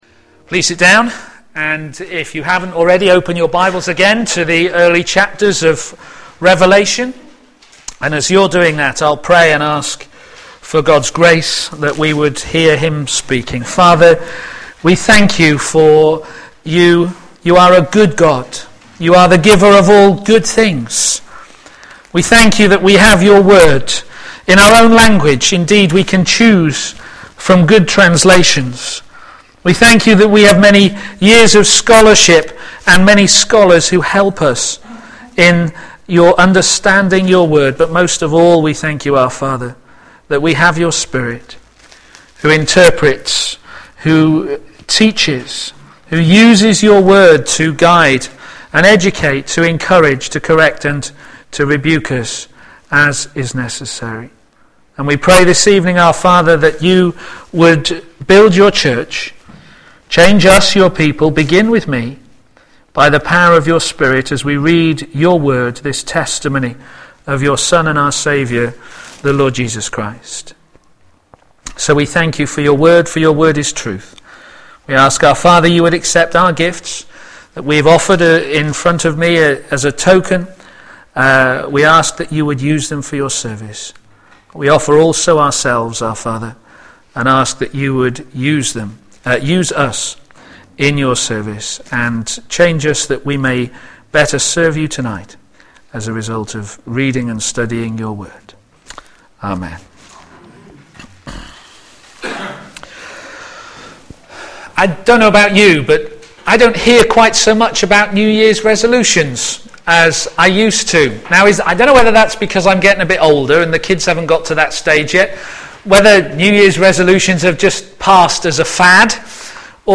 Our Glorious Hope Sermon